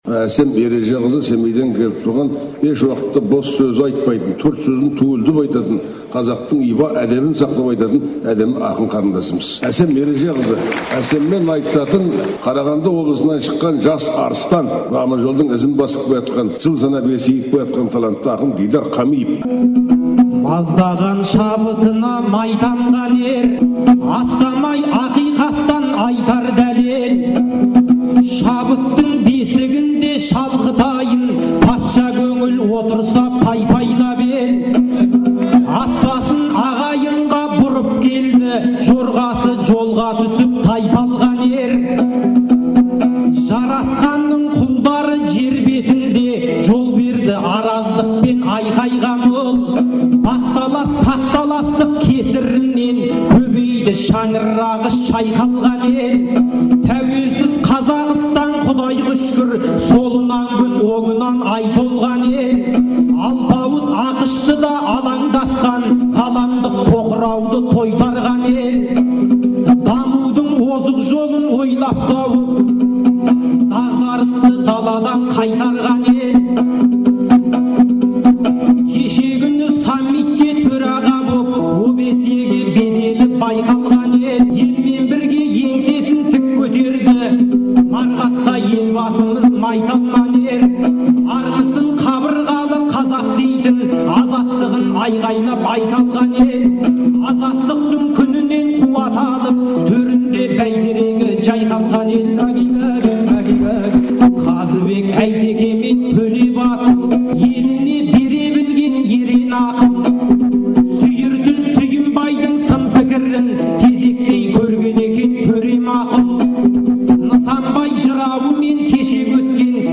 айтысы